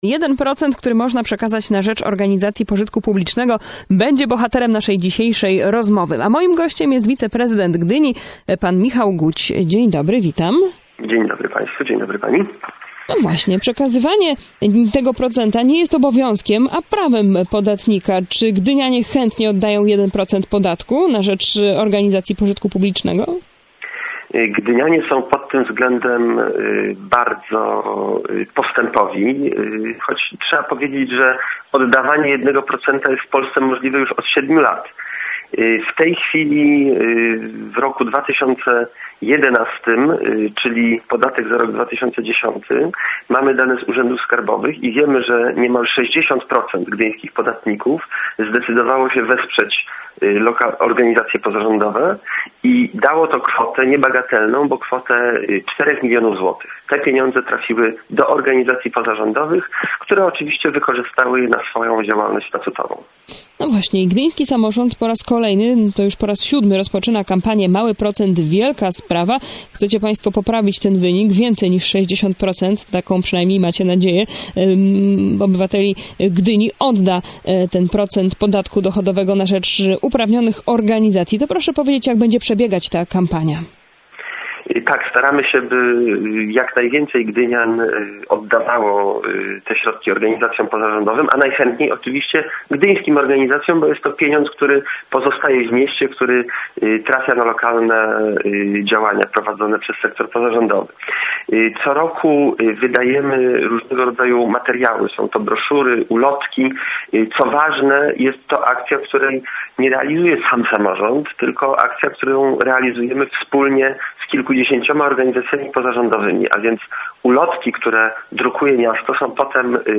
Wywiad 18.01.2012